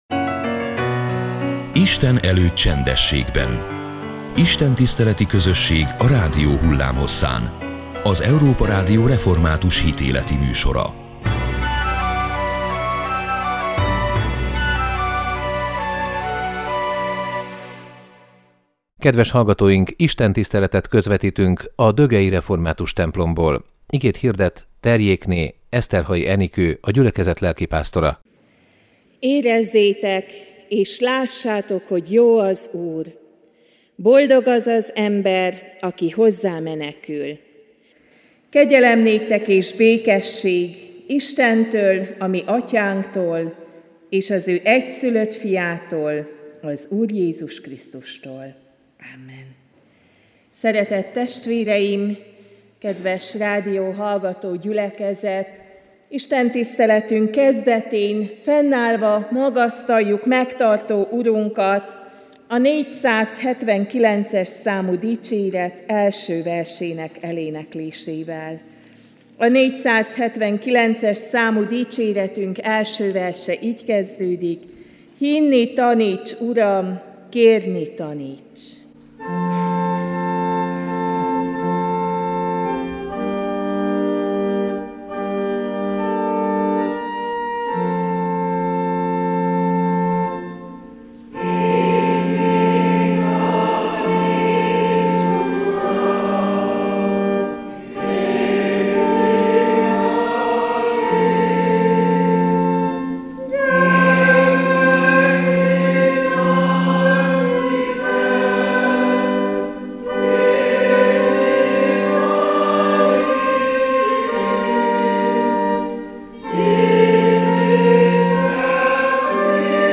Istentiszteletet közvetítettünk a dögei református templomból.